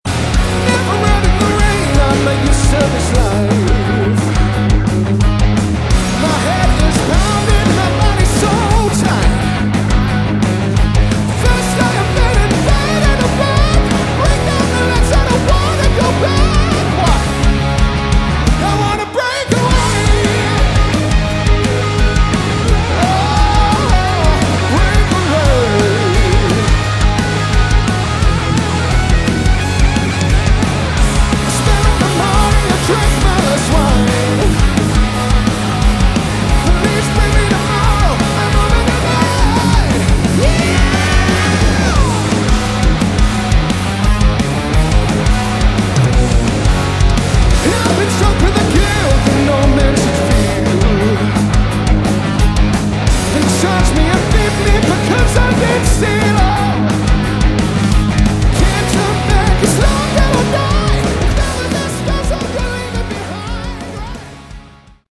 Category: Hard Rock
lead vocals
guitar
bass guitar
drums, percussion